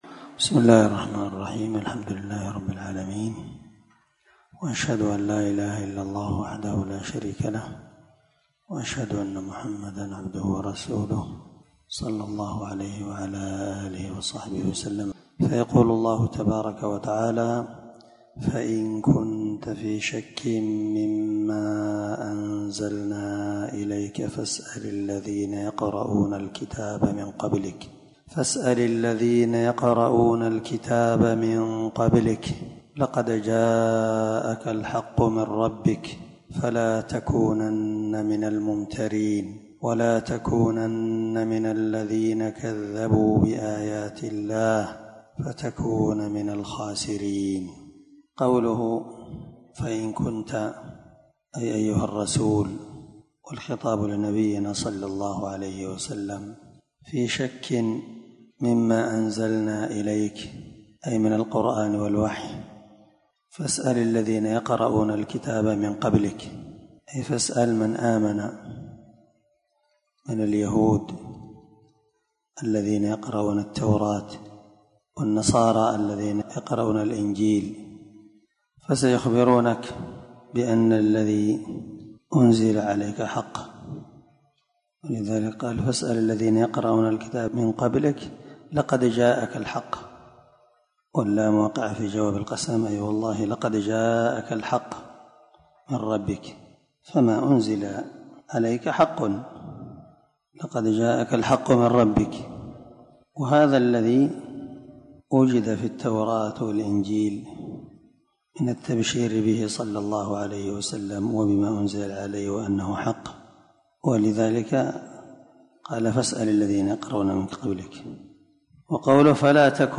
615الدرس 31 تفسير آية ( 94- 95) من سورة يونس من تفسير القران الكريم مع قراءة لتفسير السعدي
دار الحديث- المَحاوِلة- الصبيحة.